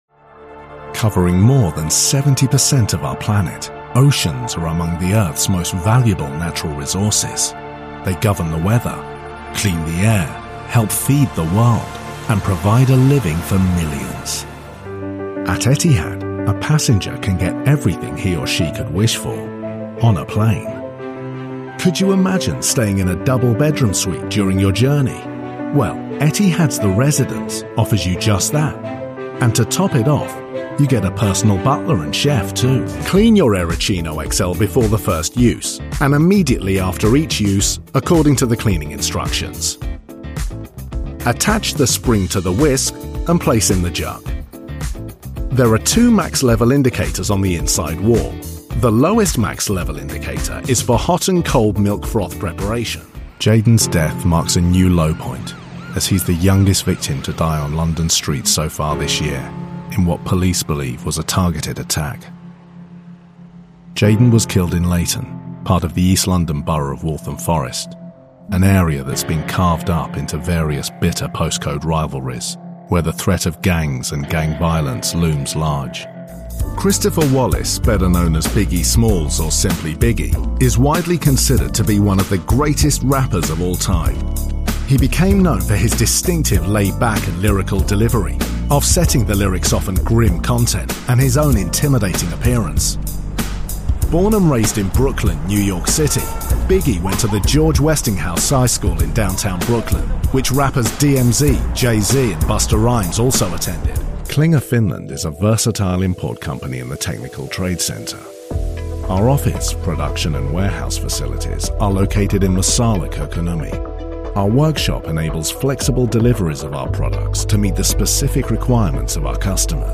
English (British)
Explainer Videos
Mic: Neumann U87